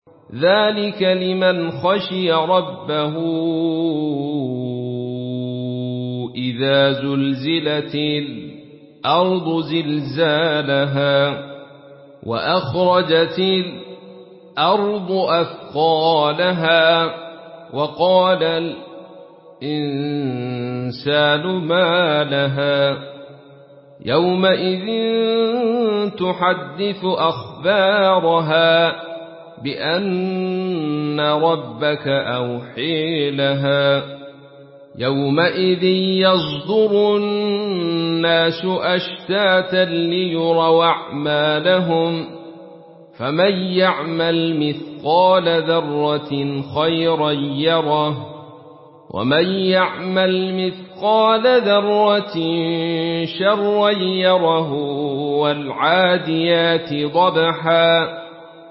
Surah Az-Zalzalah MP3 in the Voice of Abdul Rashid Sufi in Khalaf Narration
Murattal Khalaf An Hamza